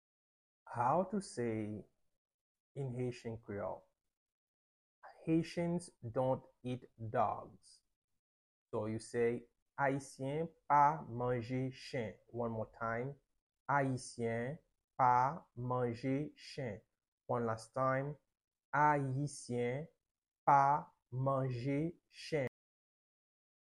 Pronunciation:
9.How-to-say-Haitian-dont-eat-dogs-in-Haitian-Creole-–-ayisyen-pa-manje-chen-with-Pronunciation.mp3